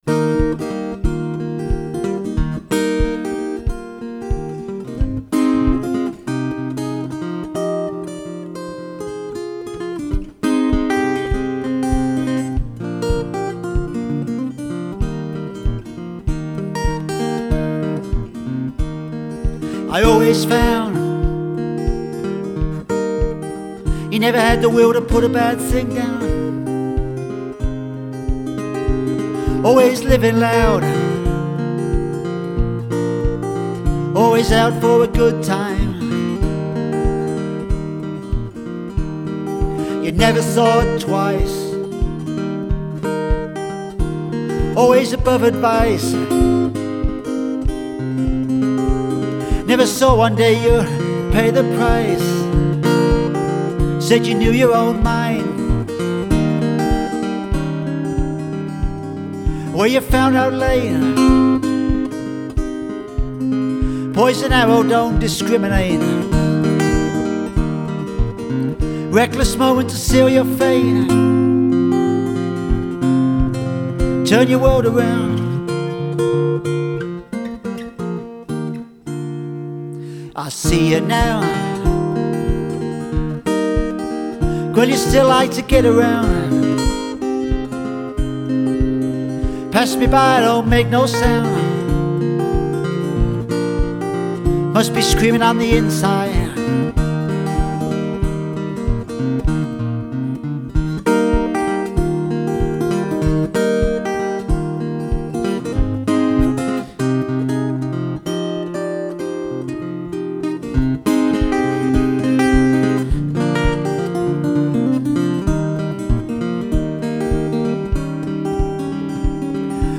Blues-Folk singer/songwriter/guitarist